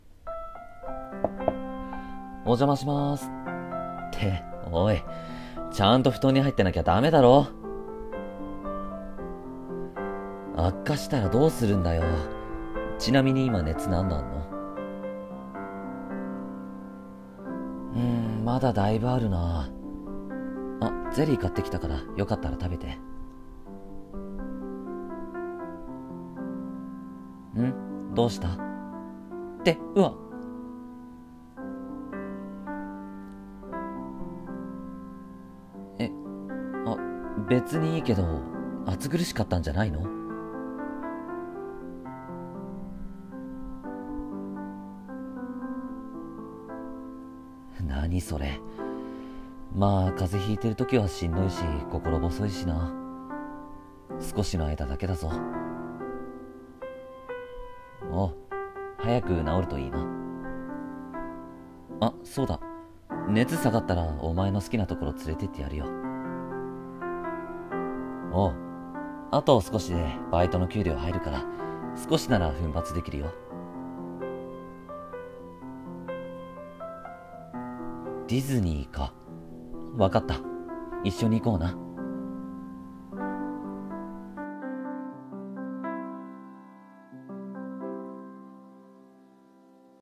【二人用声劇台本】